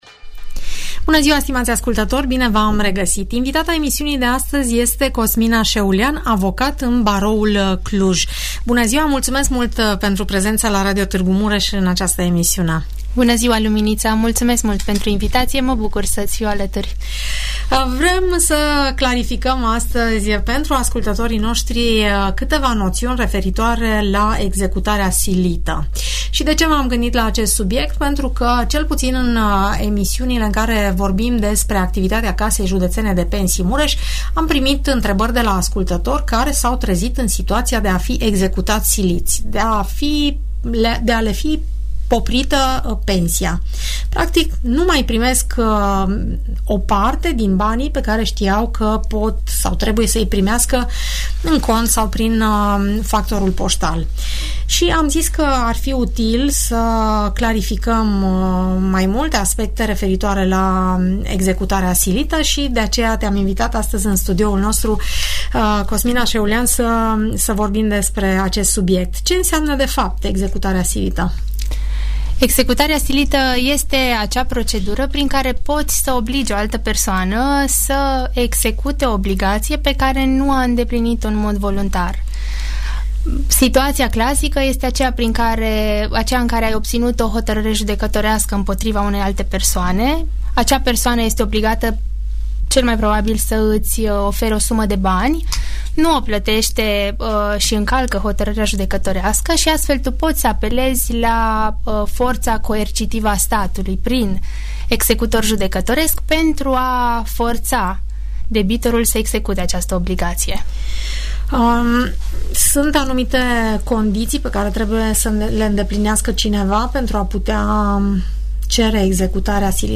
o discuție clară, aplicată și extrem de utilă